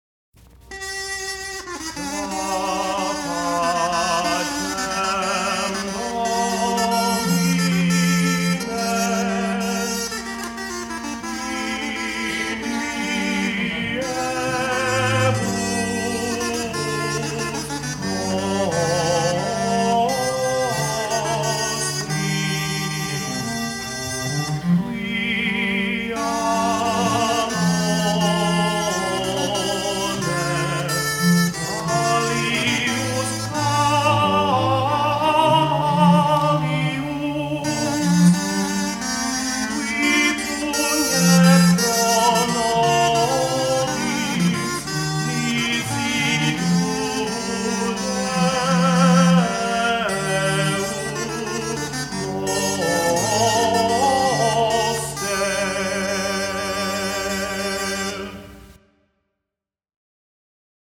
baritone